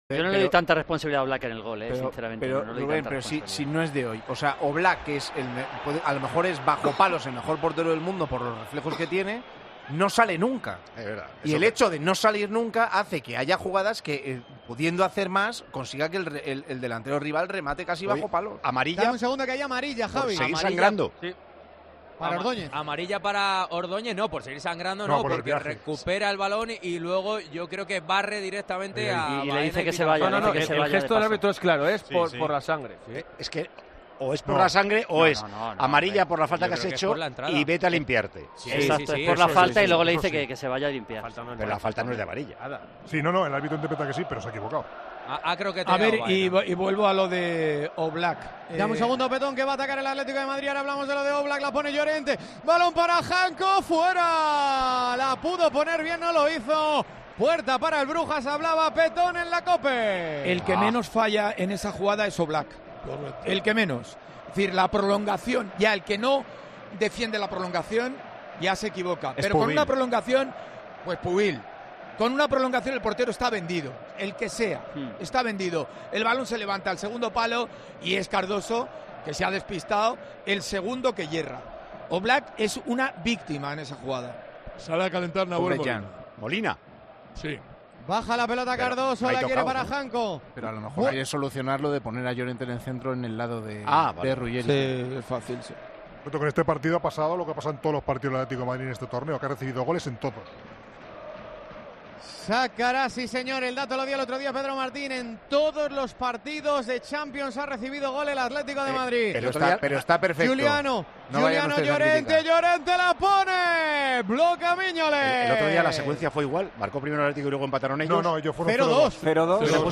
La discusión se produjo durante la retransmisión del programa, que se puede seguir en directo cada fin de semana.